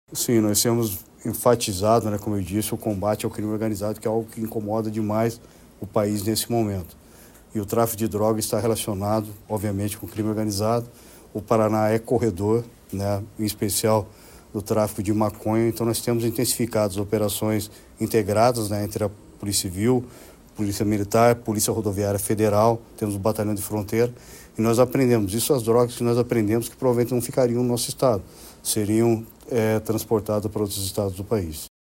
Sonora do secretário da Segurança Pública, Hudson Teixeira, sobre o aumento na apreensão de drogas nos primeiros nove meses de 2025 no Paraná